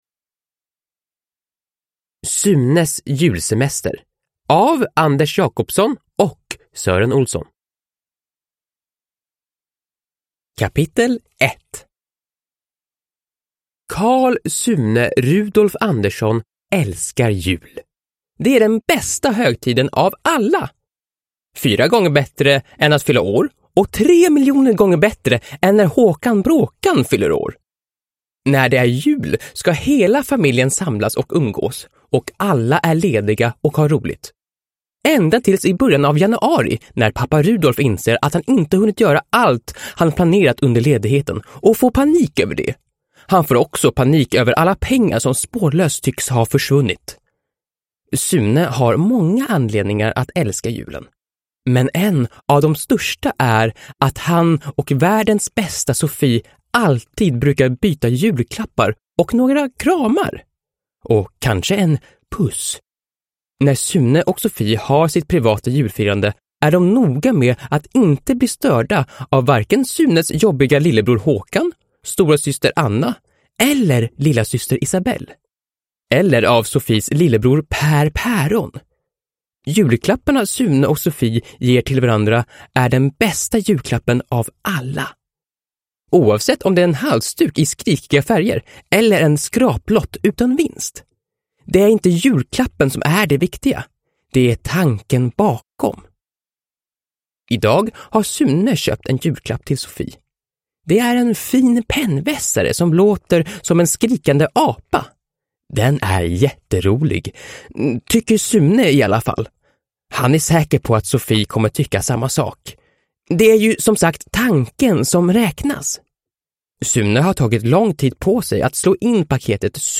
Sunes julsemester (ljudbok) av Sören Olsson